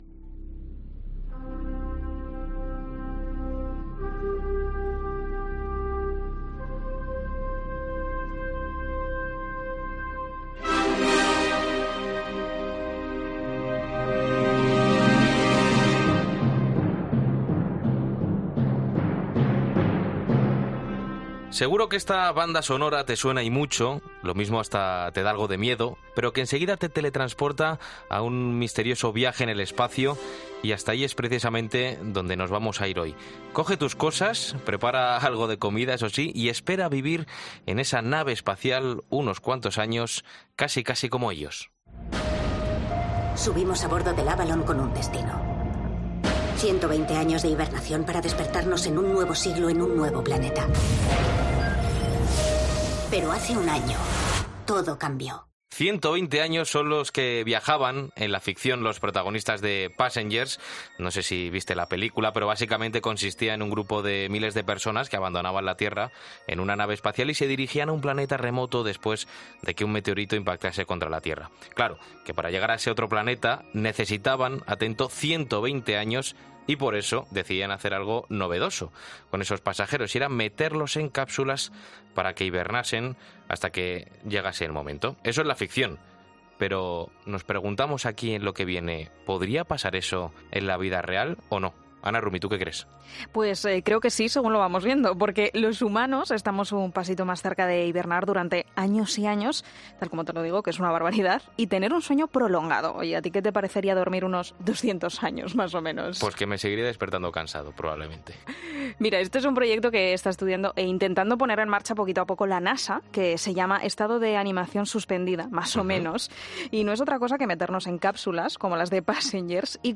En 'Lo Que Viene' hablamos sobre un nuevo proyecto de la NASA con el que prevén que el ser humano hiberne y te cóntamos con un experto cómo sería